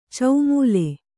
♪ caumūle